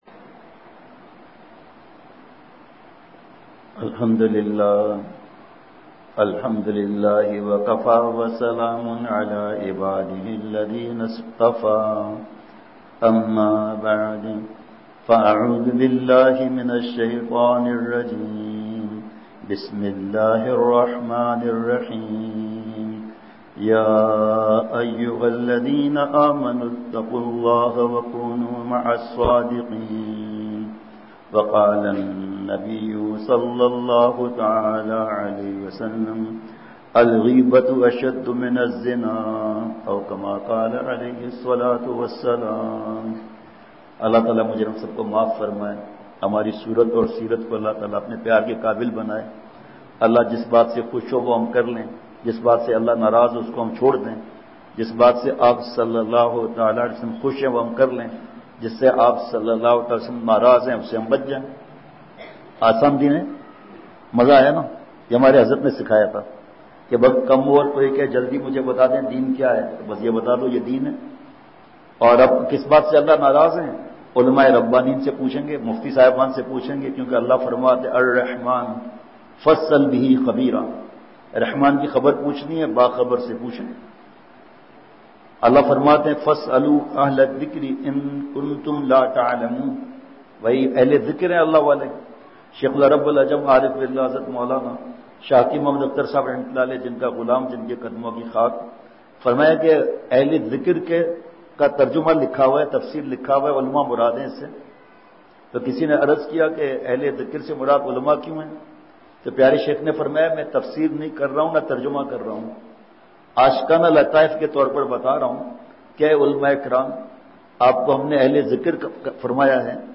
*بمقام:۔جامع عربیہ سراج المدارس نزد آلاں والاقبرستان ٹیکسلا*
*بعد مغرب بیان*